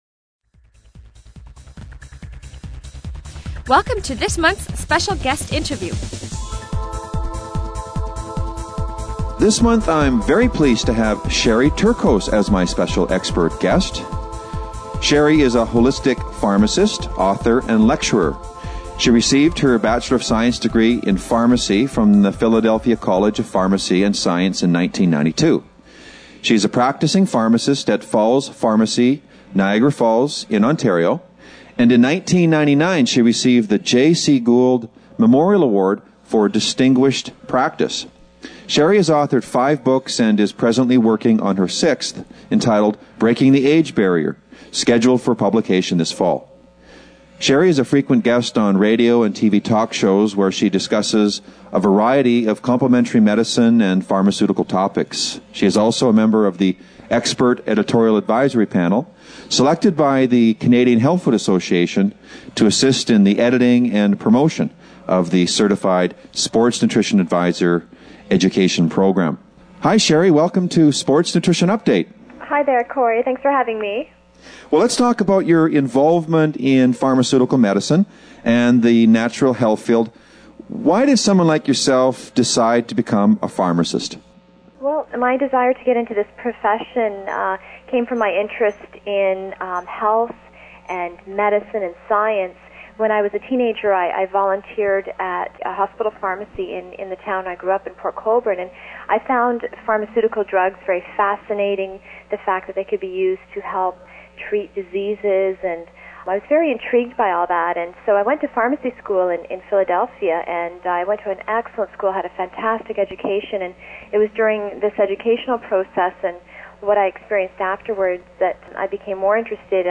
Special Guest Interview Volume 2 Number 8 V2N8c